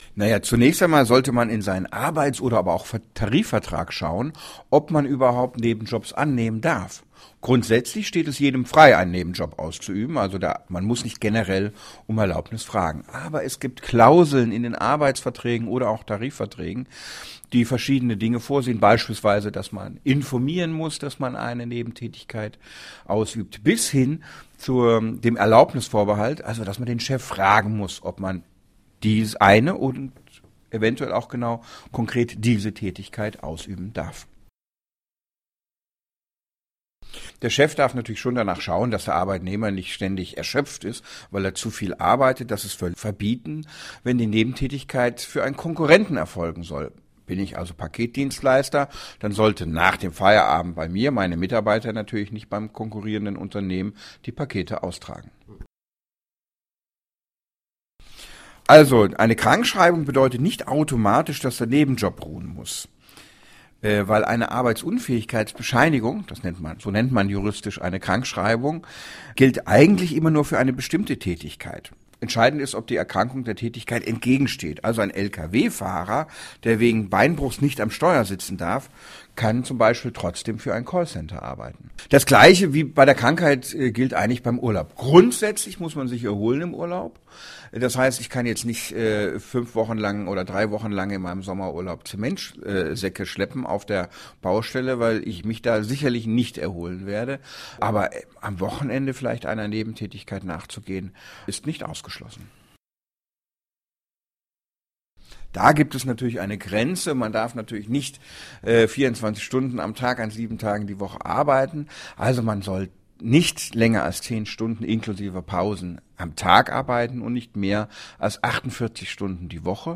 DAV, O-Töne / Radiobeiträge, Ratgeber, ,
Kollegengespräch: Was ist beim Nebenjob zu beachten?